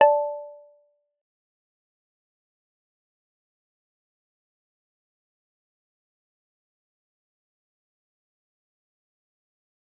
G_Kalimba-D5-f.wav